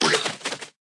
Media:RA_Hog Rider_Evo.wav UI音效 RA 在角色详情页面点击初级、经典和高手形态选项卡触发的音效